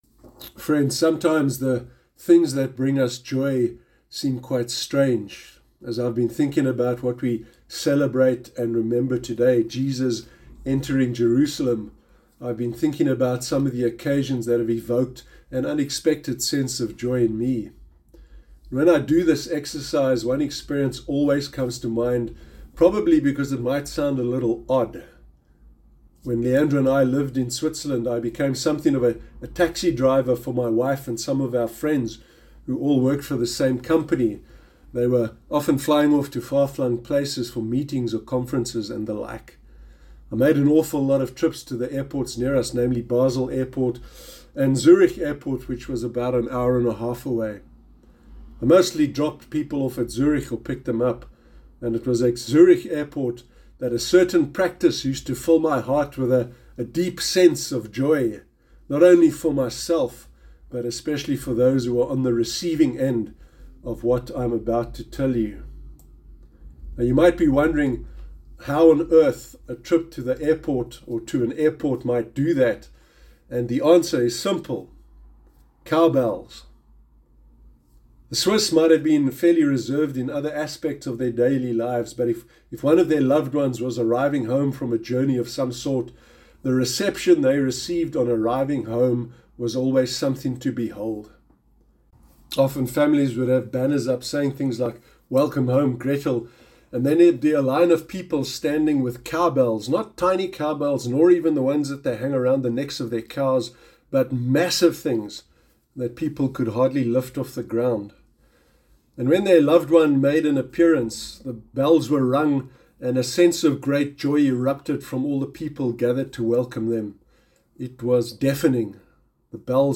Sermon Sunday sermon